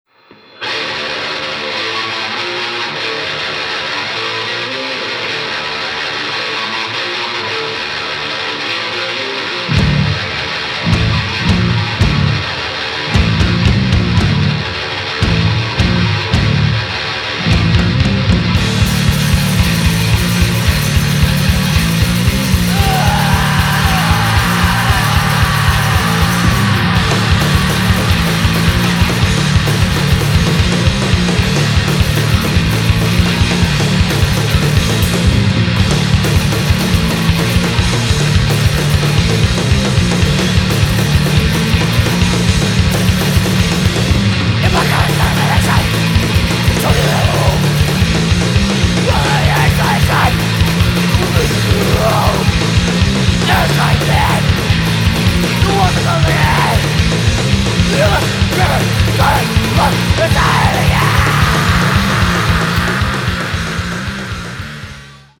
47 minutes of Rotten Metal Punk straight from Hades!!!